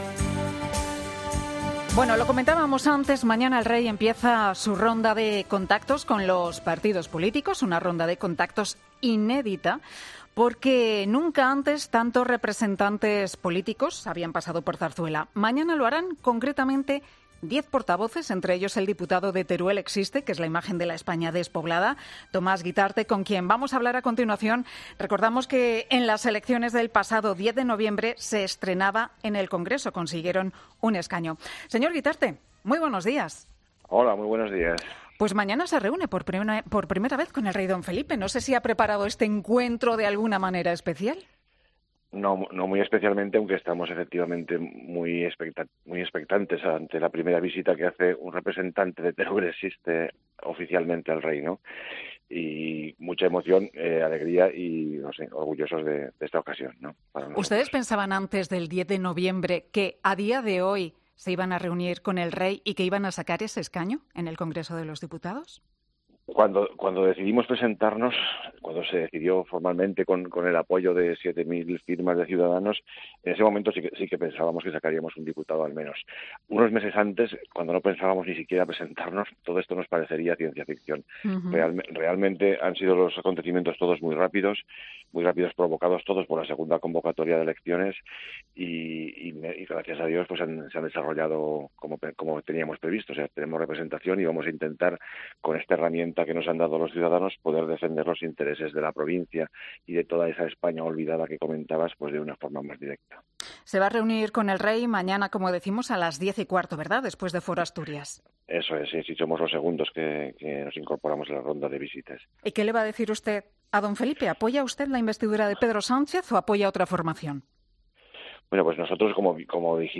Tomás Guitarte , diputado y portavoz de Teruel Existe, ha sido entrevistado este lunes en 'Herrera en COPE' a propósito de la ronda de consultas que este martes inicia el Rey.